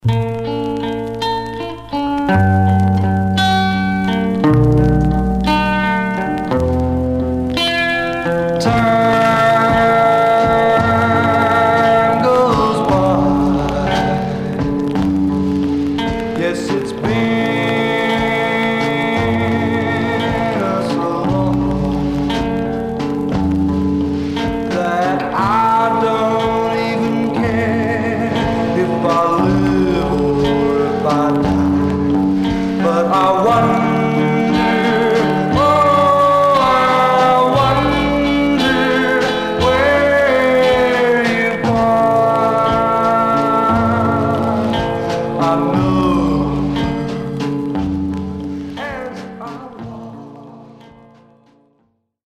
Much surface noise/wear
Mono